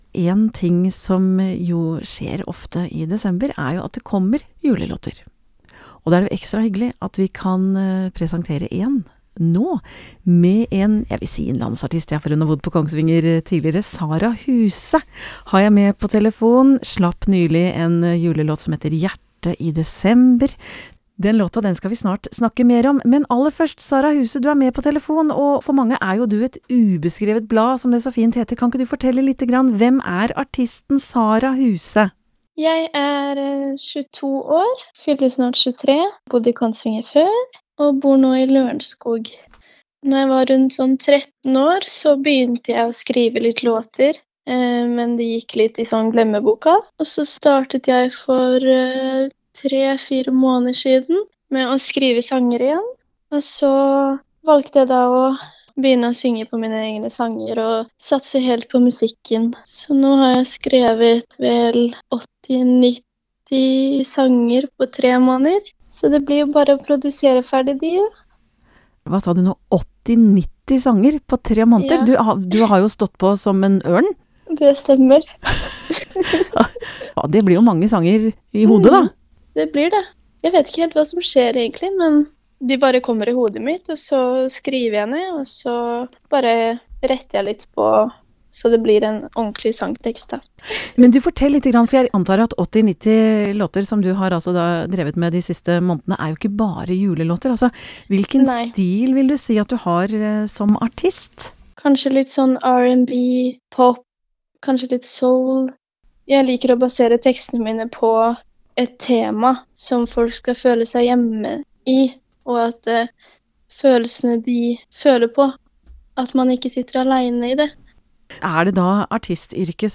Hør intervju fra Radio Kongsvinger her: https